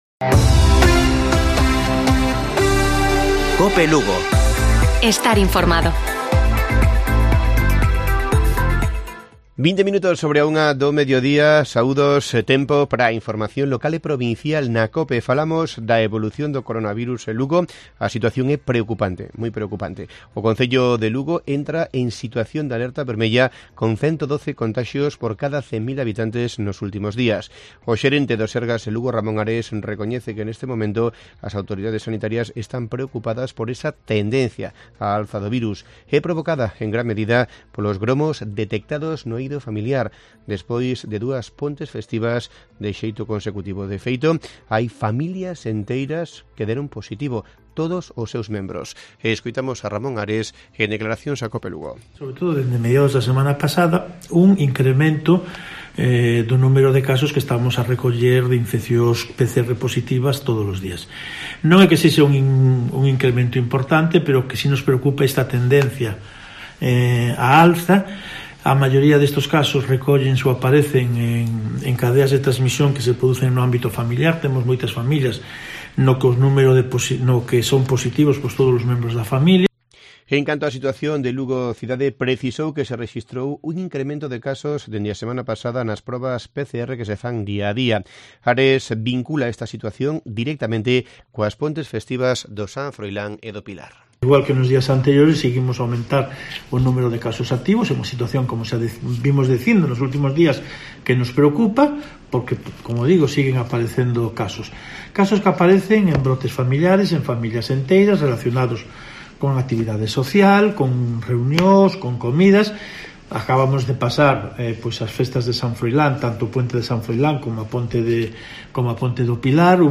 Informativo Provincial de Cope Lugo. 21 de octubre. 13:20 horas